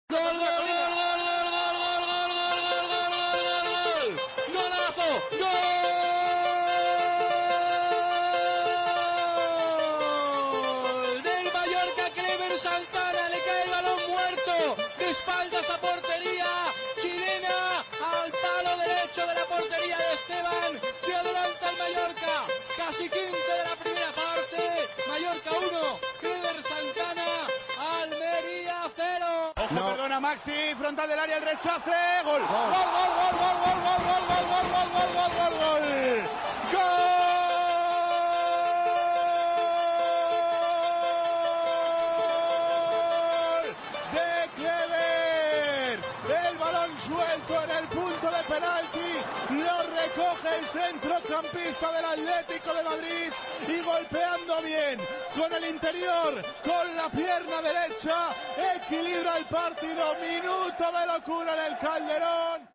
narraban así dos goles del jugador que militó en el Mallorca y en el Atlético.